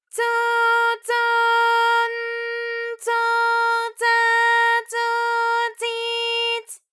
ALYS-DB-001-JPN - First Japanese UTAU vocal library of ALYS.
tso_tso_n_tso_tsa_tso_tsi_ts.wav